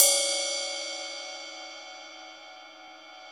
CYM XRIDE 3C.wav